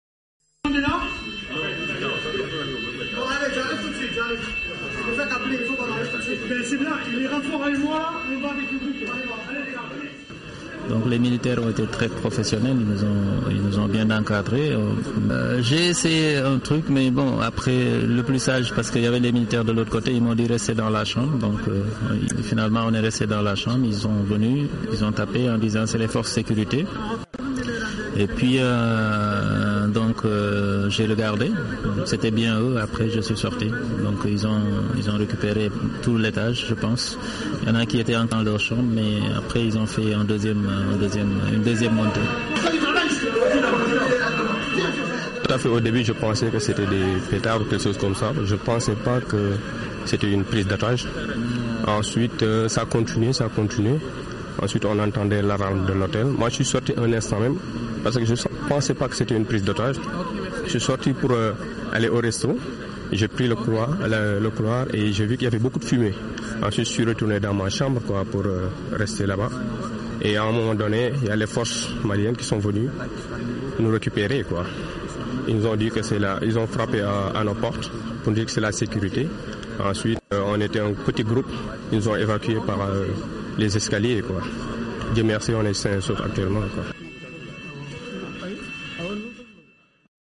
Témoignages maintenant de deux otages qui ont pu être exfiltres de l’hôtel par les forces de sécurité.